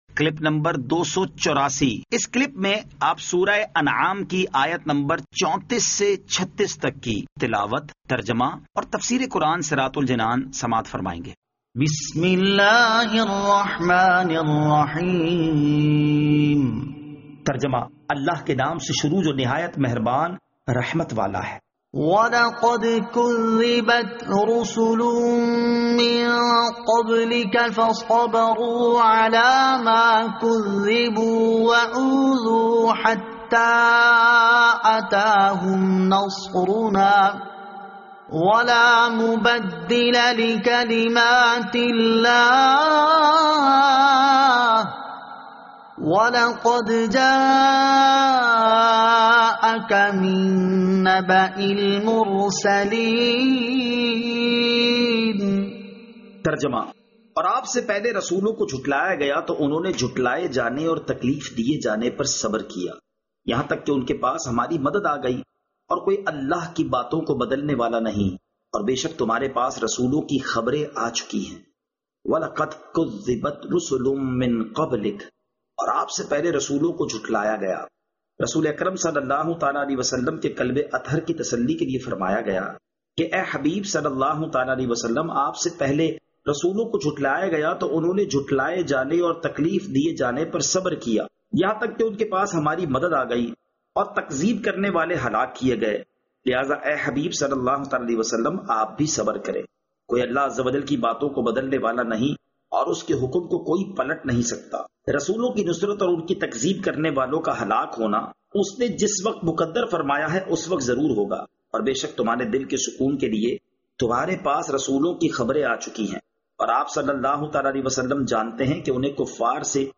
Surah Al-Anaam Ayat 34 To 36 Tilawat , Tarjama , Tafseer